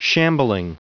Prononciation du mot shambling en anglais (fichier audio)
Prononciation du mot : shambling